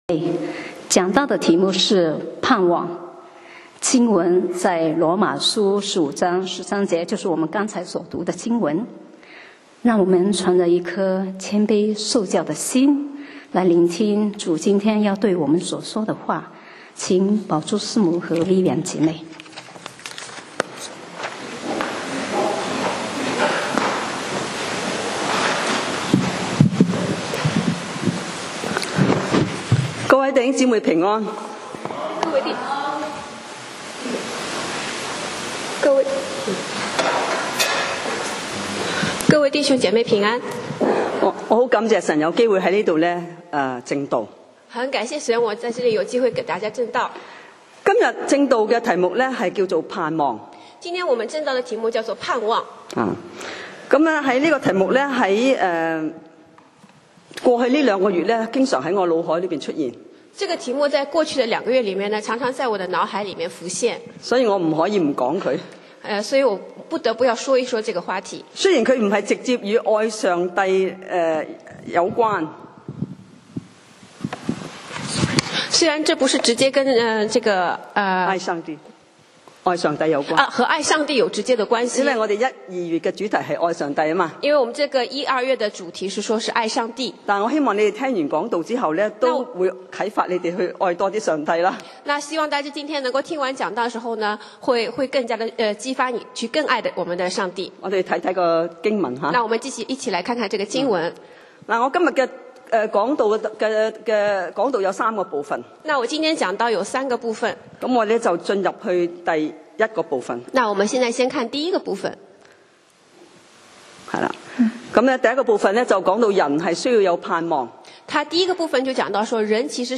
講道 Sermon 題目 Topic：盼望 經文 Verses：羅馬書15:13。